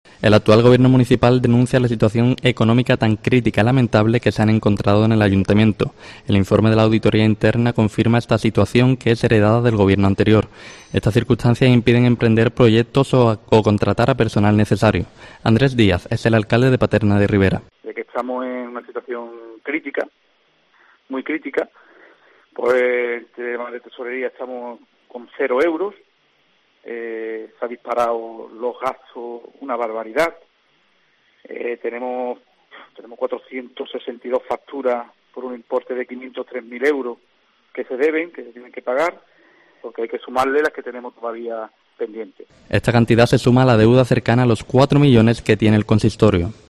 Andrés Díaz, alcalde de Paterna de Rivera